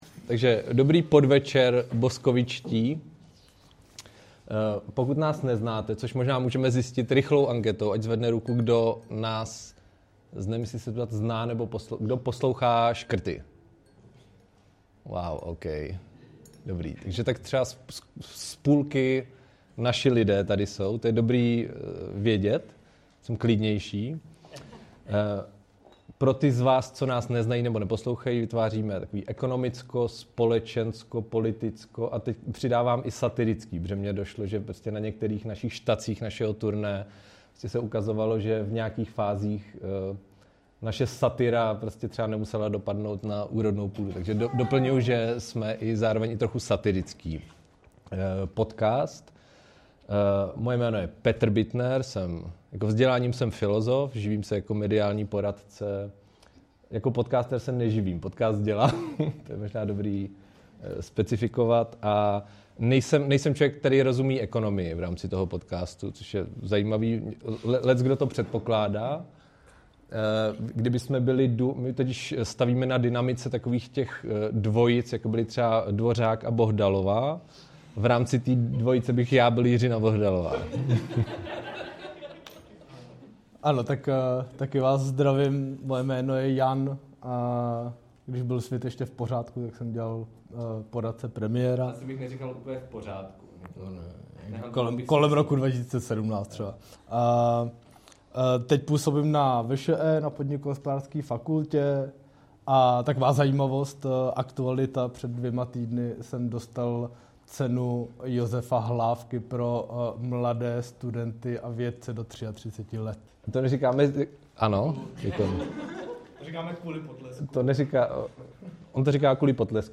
Diskuzní cyklus Perspektivy byl v roce 2025 finančně podpořen z grantového systému města Boskovice.